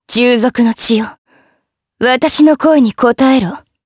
Lewsia_B-Vox_Skill2_jp.wav